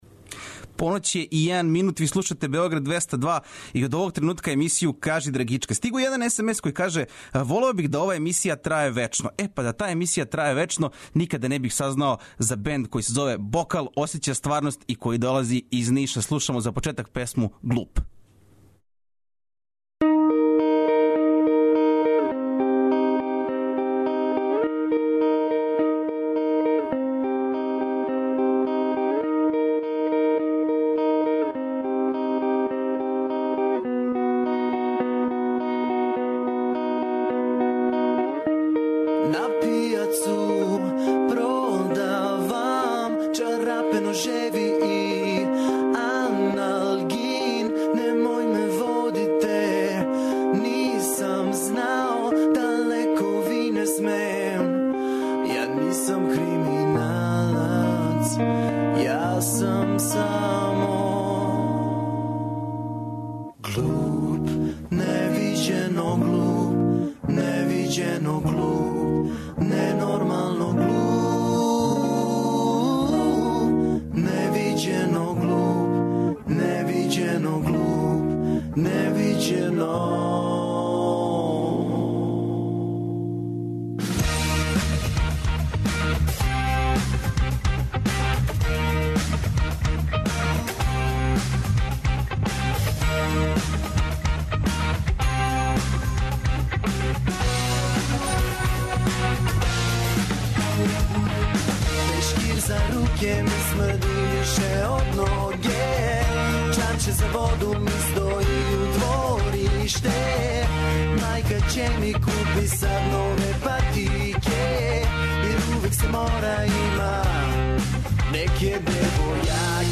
Ноћас са нама духовите Нишлије - Бокал осећа стварност! Послушајте мало рокенрола са јужне пруге!